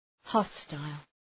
Προφορά
{‘hɒstəl, ‘hɒstaıl}